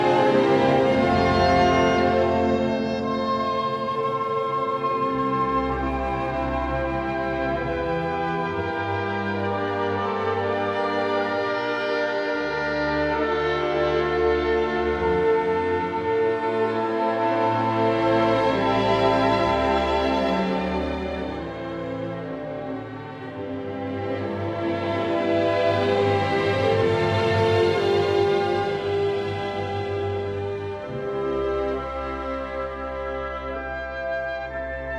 Classical
Жанр: Классика